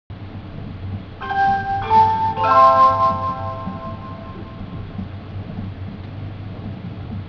285系車内チャイム
281系「はるか」の途中駅用チャイムと同じ３打点を使用しています。放送の冒頭と最後に用いられており、肝心の放送は車掌による肉声となります。個室でも小さなスピーカーから流れるので、乗ってしまえば収録は楽です。